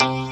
Hits